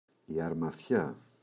αρμαθιά, η [arma’θça] – ΔΠΗ
αρμαθιά, η [armaθça]: σύνολο ομοειδών πραγμάτων, περασμένων σε νήμα.